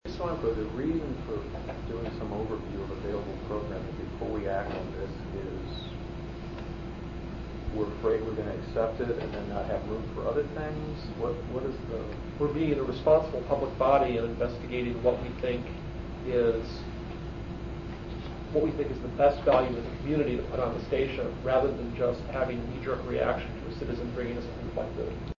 Audio clip from the meeting: